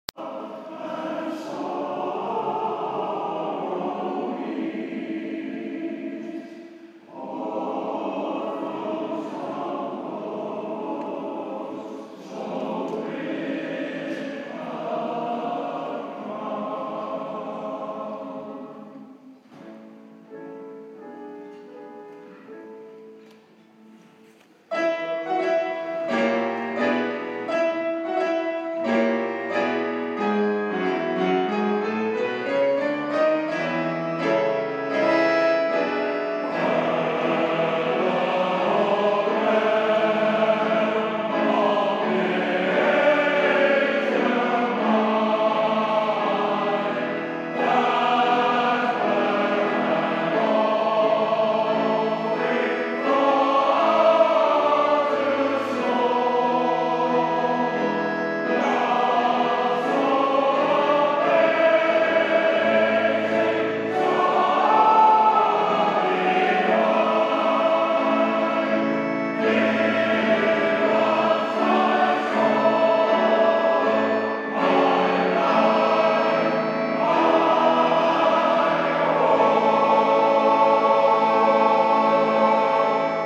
Rehearsals under way at Regents Hall